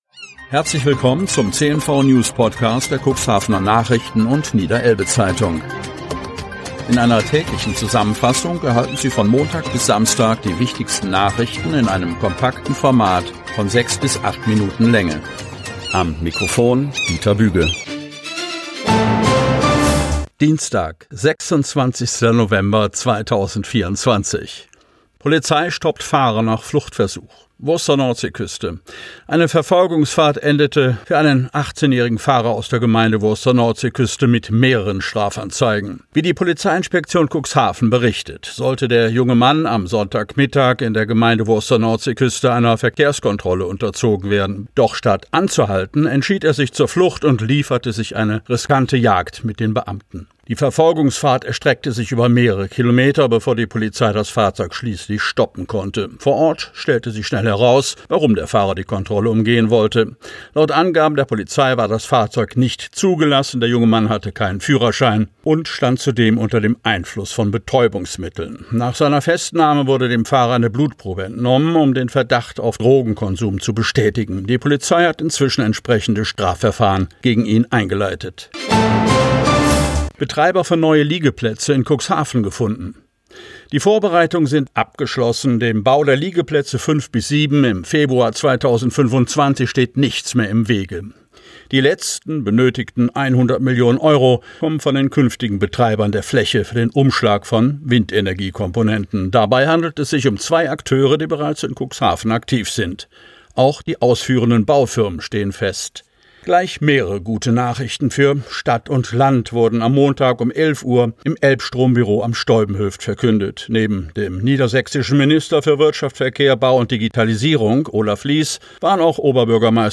Ausgewählte News der Cuxhavener Nachrichten + Niederelbe-Zeitung am Vorabend zum Hören!